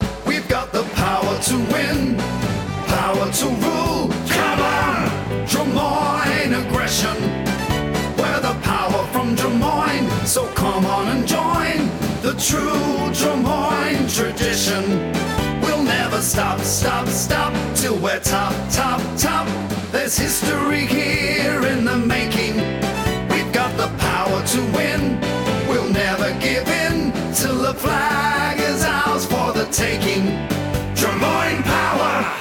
and the (shorter) version we sing after games…